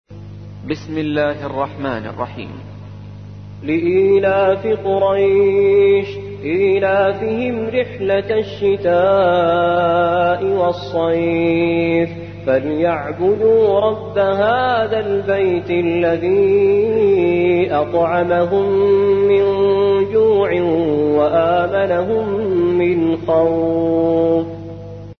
106. سورة قريش / القارئ